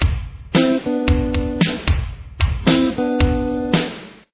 Intro Riff
Guitar 2